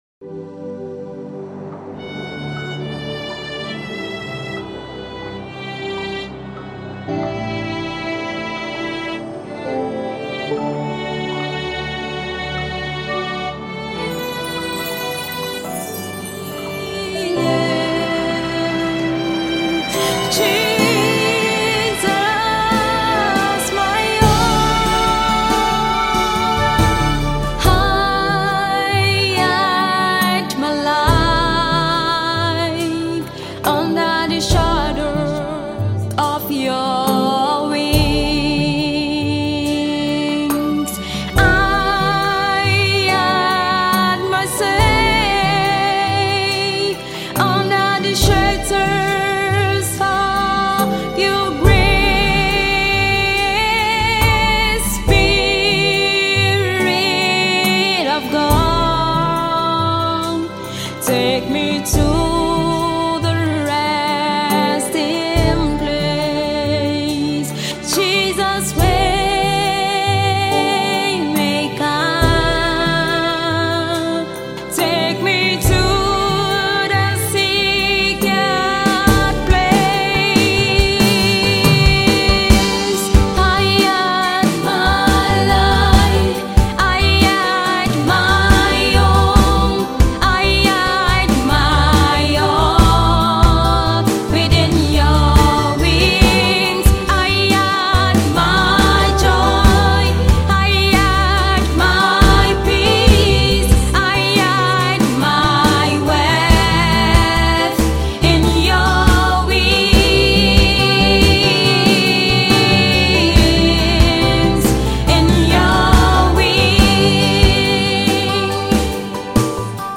Prolific gospel music minister